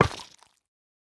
Minecraft Version Minecraft Version latest Latest Release | Latest Snapshot latest / assets / minecraft / sounds / block / sculk_catalyst / place5.ogg Compare With Compare With Latest Release | Latest Snapshot